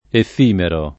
vai all'elenco alfabetico delle voci ingrandisci il carattere 100% rimpicciolisci il carattere stampa invia tramite posta elettronica codividi su Facebook effimero [ eff & mero ] (antiq. efimero [ ef & mero ]) agg.